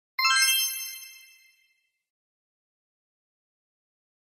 Sonneries » Sons - Effets Sonores » Bonne réponse